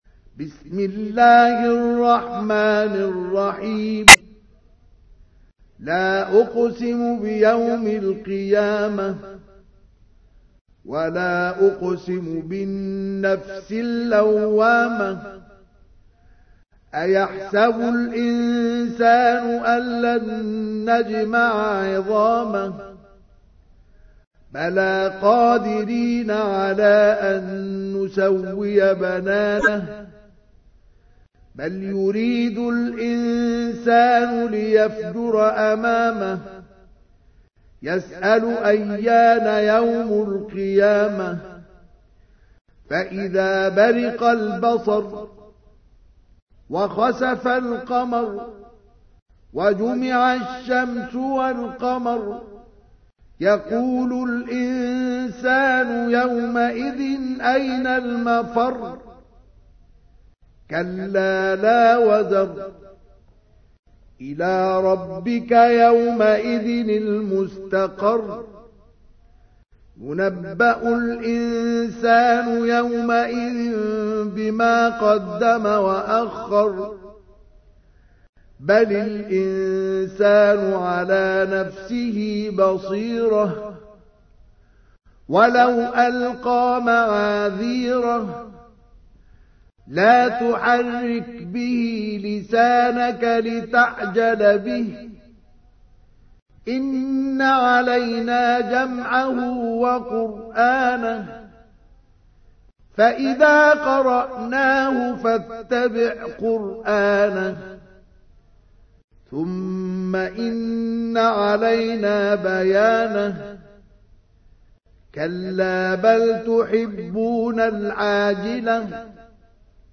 تحميل : 75. سورة القيامة / القارئ مصطفى اسماعيل / القرآن الكريم / موقع يا حسين